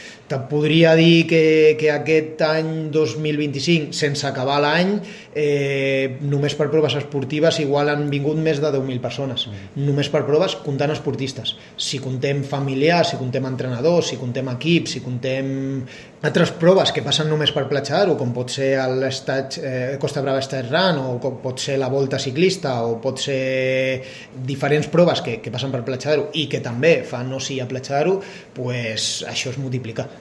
Durant aquest any 2025, encara en curs, s’estima que més de 10.000 turistes han visitat Castell d’Aro, Platja d’Aro i s’Agaró per motius purament esportius. El regidor d’Esports i Salut de l’Ajuntament del municipi, Marc Medina, apunta que aquestes dades només compten els esportistes i que, a més, s’hi han de sumar acompanyants o entrenadors.